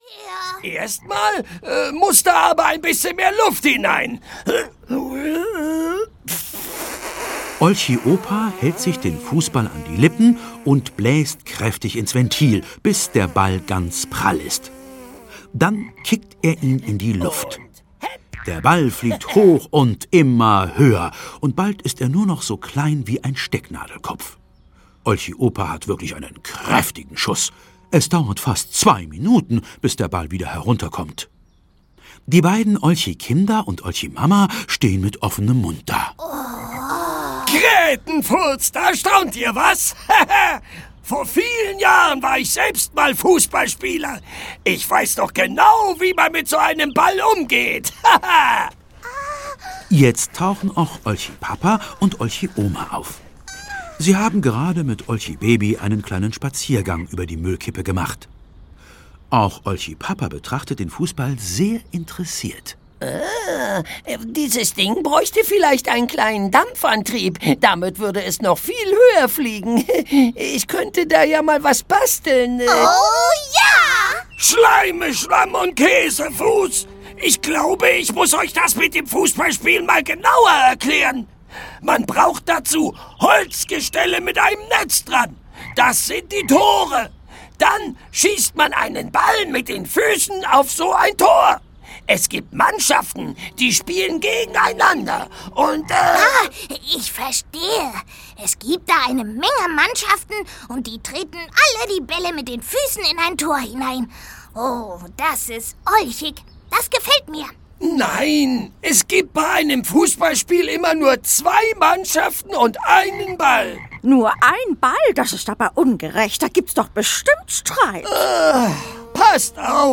Die Olchis werden Fußballmeister - Erhard Dietl - Hörbuch